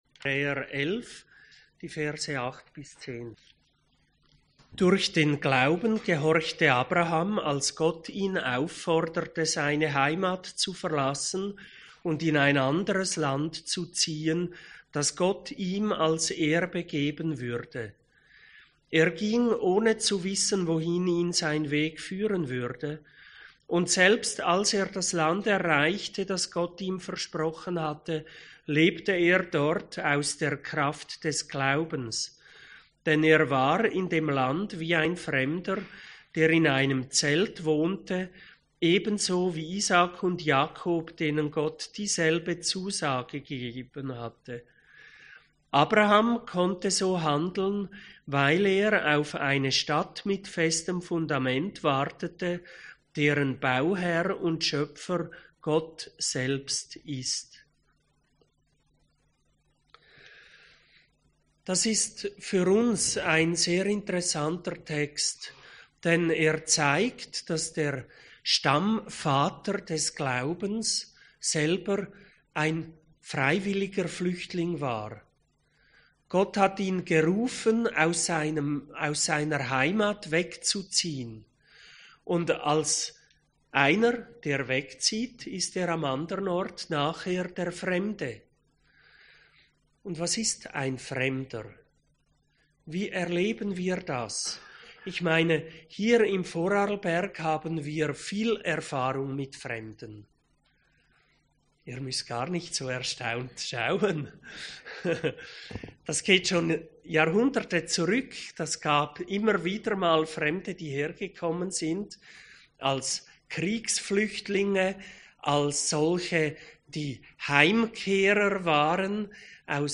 Eine Predigt zum Welt-Flüchtlingssonntag 2018.